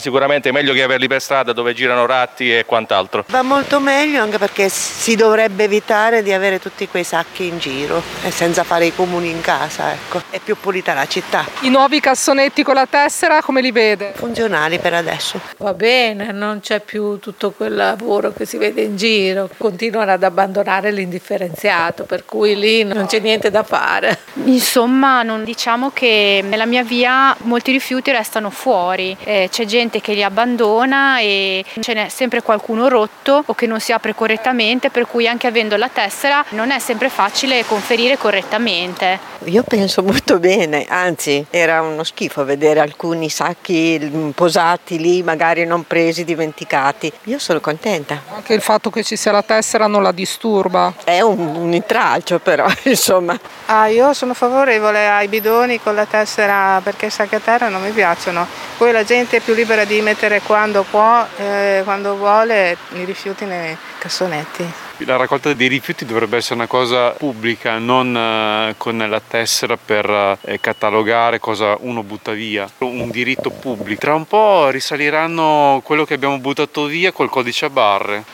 Qui sotto le opinioni di alcuni residenti